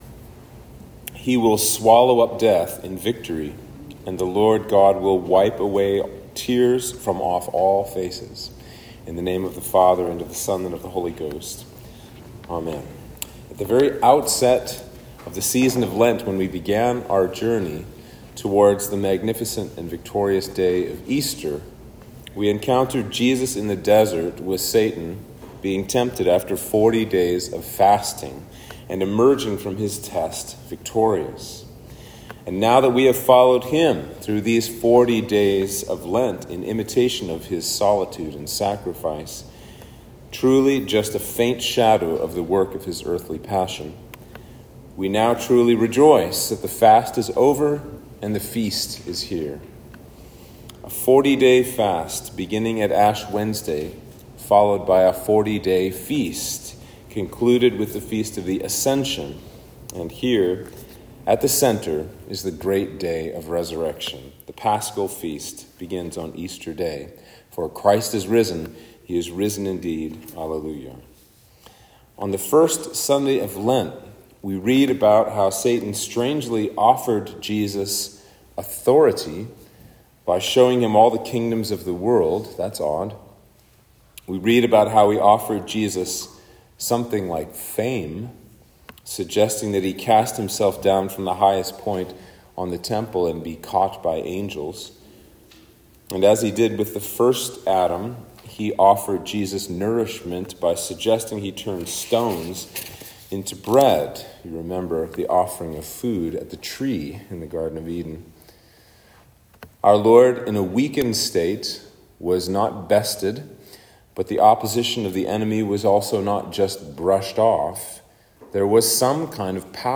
Sermon for Easter Day